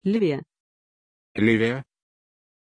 Pronunciation of Livia
pronunciation-livia-ru.mp3